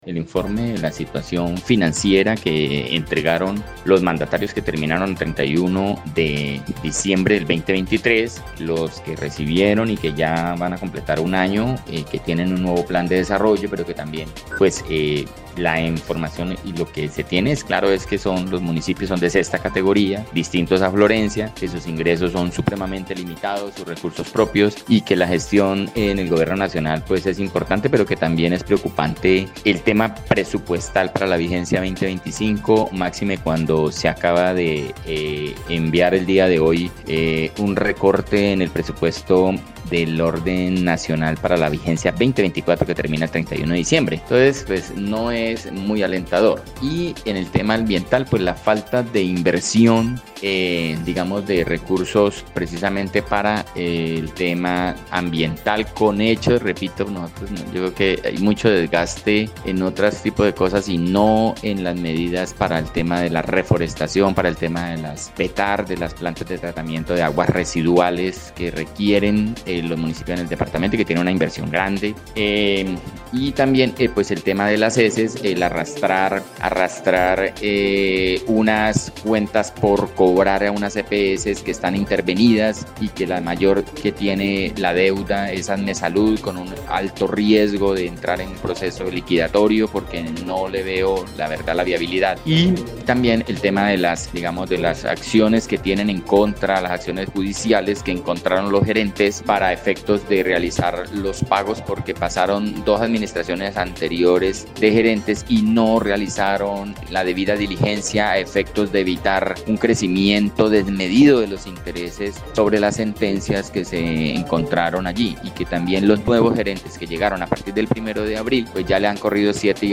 Así quedó en evidencia tras el más reciente informe presentado por el Contralor Departamental del Caquetá, Hermes Torres Núñez, ante la asamblea seccional, donde se evidencia una preocupación por los presupuestos de la vigencia 2025, por los recortes económicos que vienen desde el gobierno nacional.
01_CONTRALOR_HERMES_TORRES_INFORME.mp3